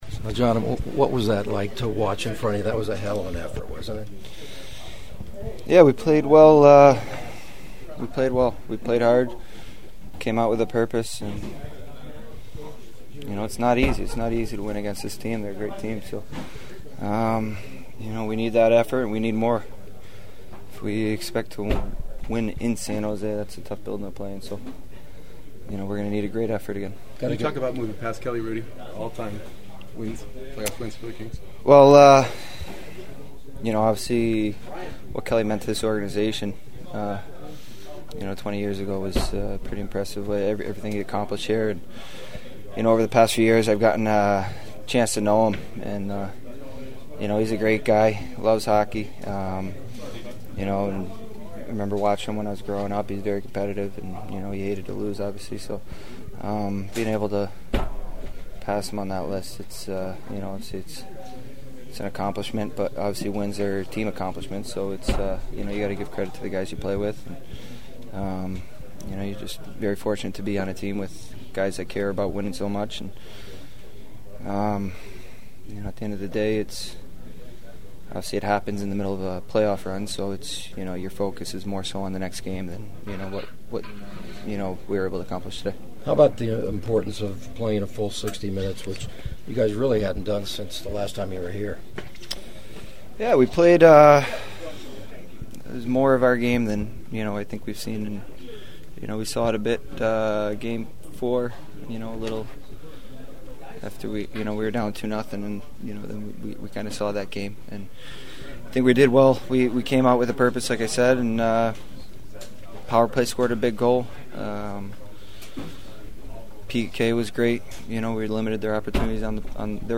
The sounds of the postgame are ahead from a confident Kings locker room: